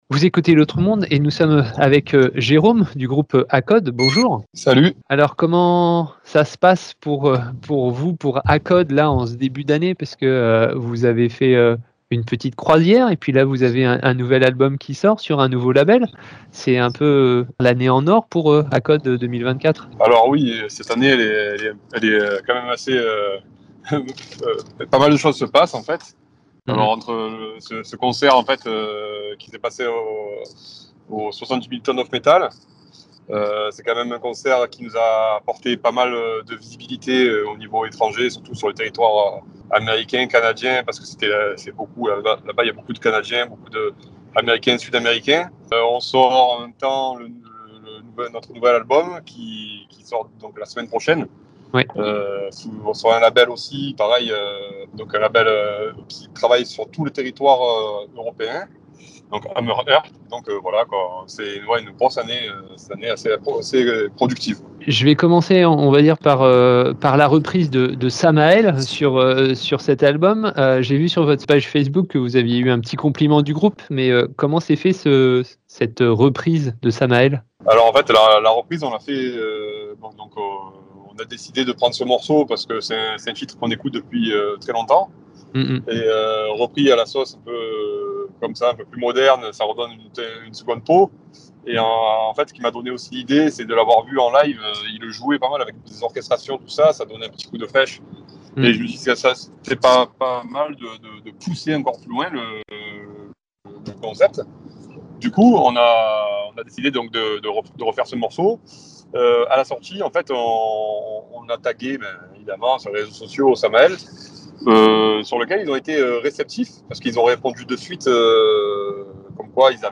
Interview du groupe ACOD enregistrée le 19.04.2024